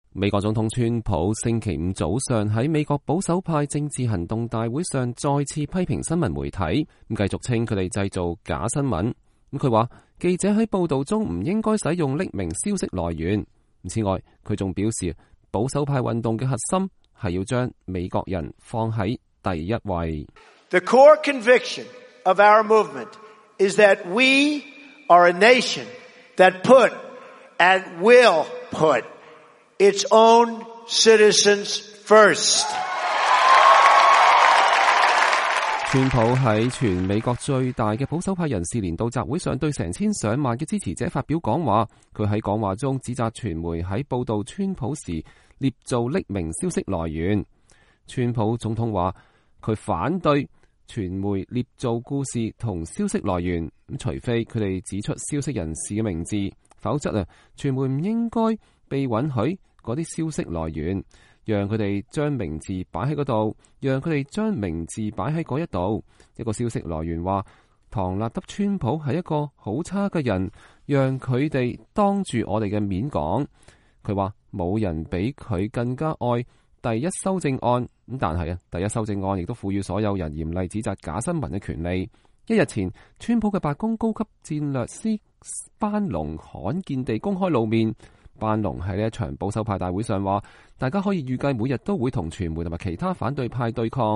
川普在全美國最大的保守派人士的年度集會上對成千上萬的支持者發表講話。
他講這句話的時候，現場爆發出“USA、USA”的歡呼聲。